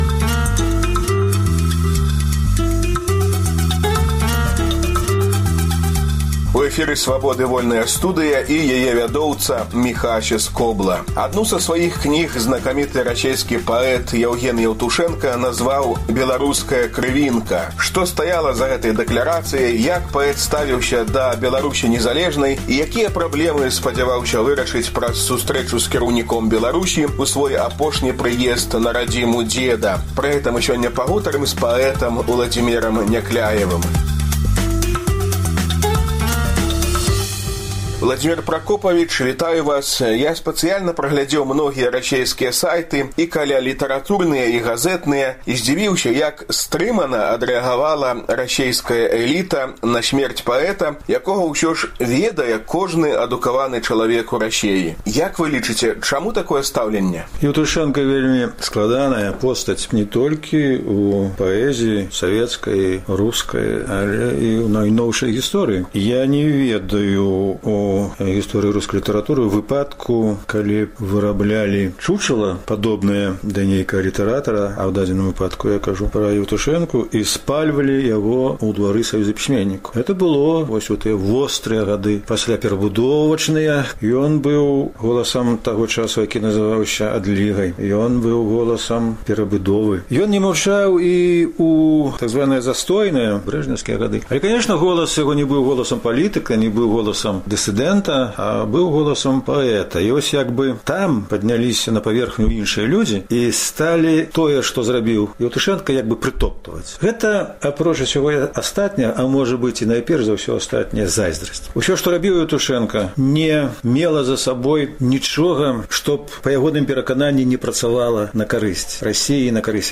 Перадача прысьвечаная памяці расейскага паэта Яўгена Еўтушэнкі, які адну са сваіх кніг назваў “Беларуская крывінка”. Госьцем у студыі – Уладзімер Някляеў.